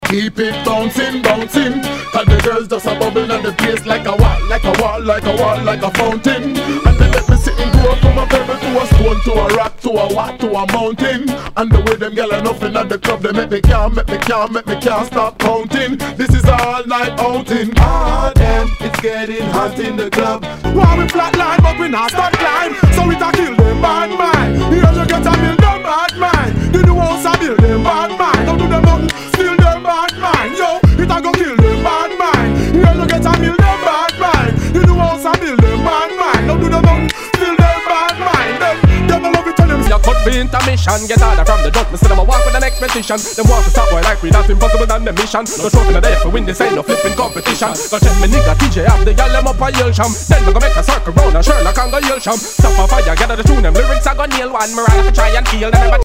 類別 雷鬼
全体にチリノイズがは入ります